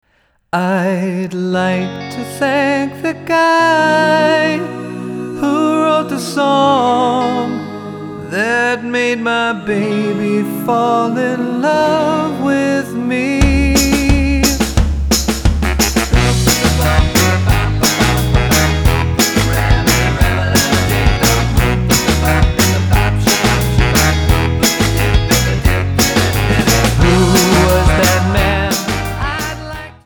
--> MP3 Demo abspielen...
Tonart:B-C Multifile (kein Sofortdownload.